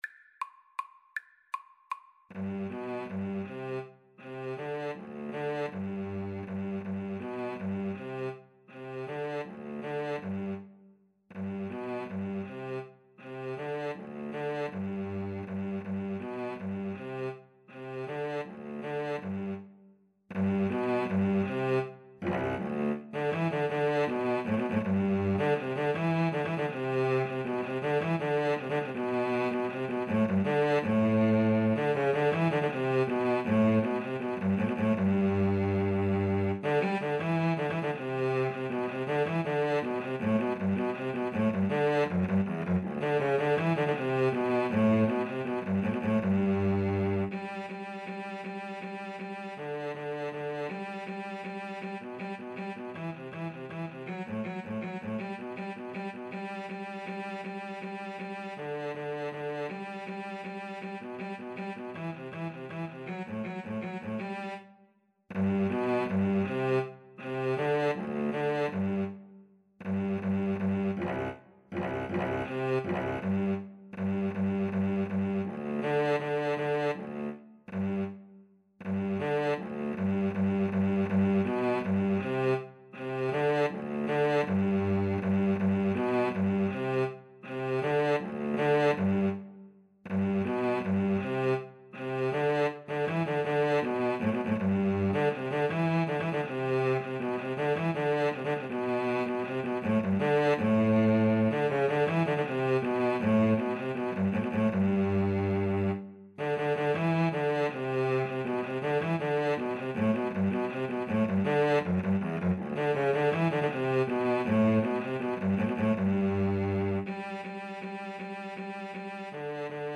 3/4 (View more 3/4 Music)
Allegro Vivo = 160 (View more music marked Allegro)
Classical (View more Classical Violin-Cello Duet Music)